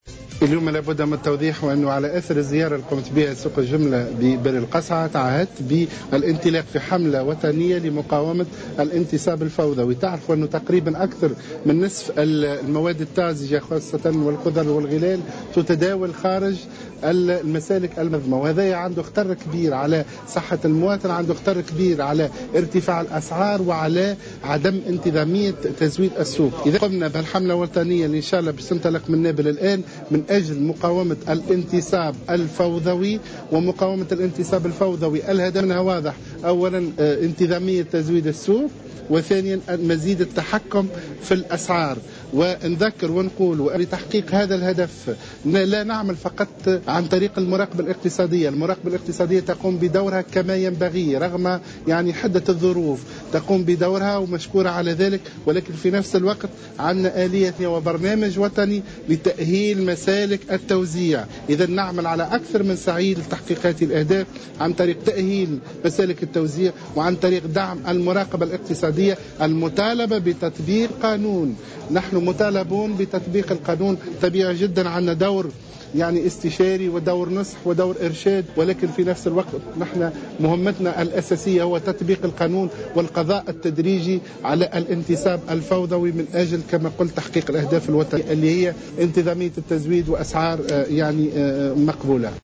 Le ministre du commerce Mohsen Hassan, a déclaré ce mercredi 3 février 2016, que de nouvelles mesures pour encourager l’exportation, seront annoncé dans deux jours par le ministère.